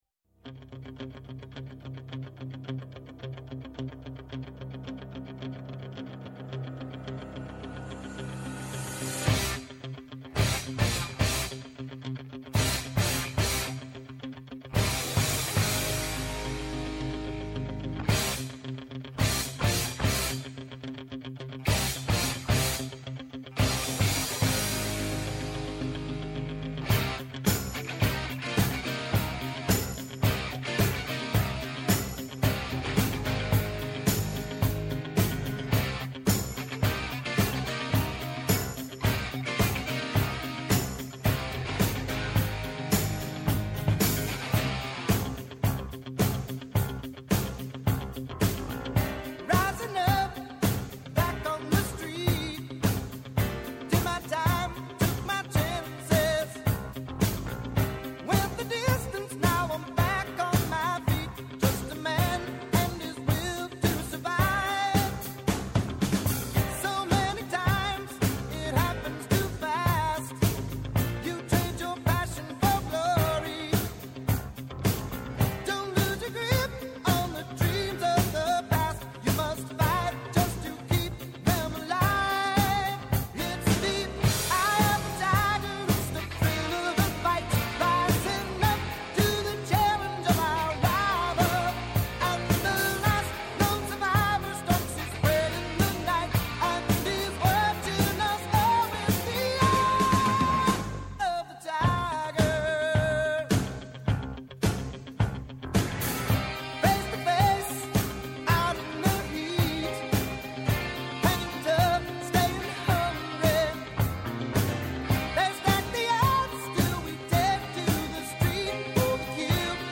ΠΡΩΤΟ ΚΑΙ ΣΤΑ ΣΠΟΡ, η κοινωνική διάσταση του αθλητισμού, από τις συχνότητες του Πρώτου Προγράμματος της Ελληνικής Ραδιοφωνίας κάθε Σάββατο 13:00-14:00 το μεσημέρι από το Πρώτο Πρόγραμμα.